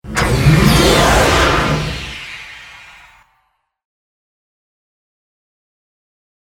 Звуки инопланетян
На этой странице собраны необычные аудиозаписи, имитирующие голоса и технологии внеземных существ.